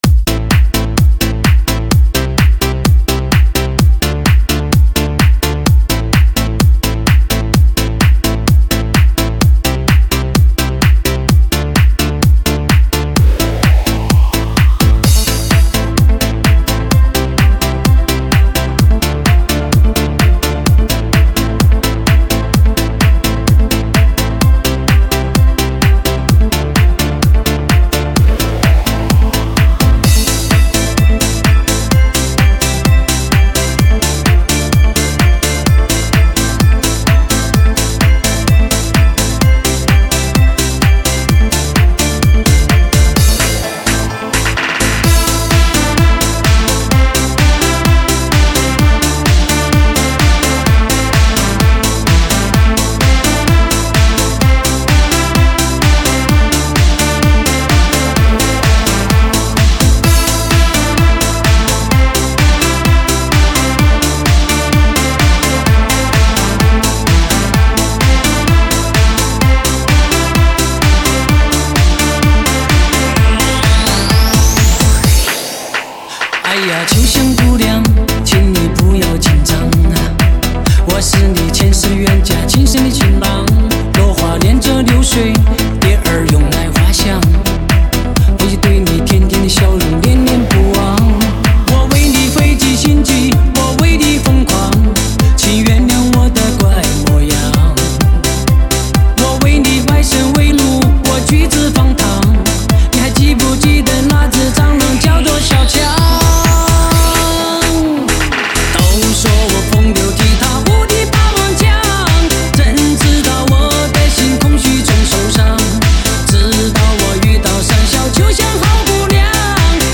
极品劲爆迪士高
撼世巨作的最佳舞曲